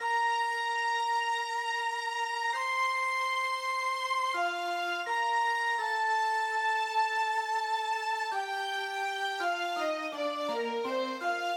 没有对抗的合成器弦乐
Tag: 83 bpm Hip Hop Loops Synth Loops 1.95 MB wav Key : A